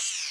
ZOOM-OUT.mp3